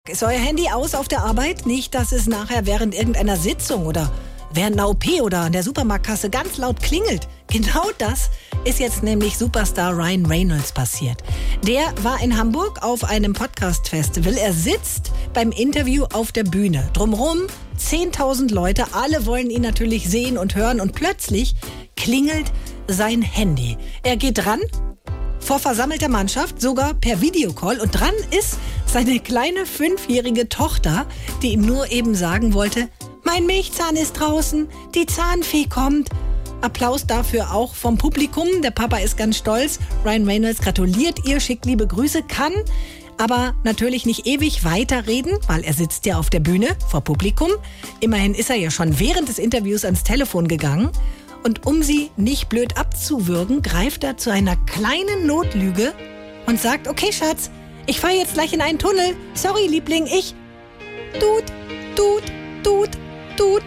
Nachrichten OMR in Hamburg: Wichtiger Anruf für Ryan Reynolds 😆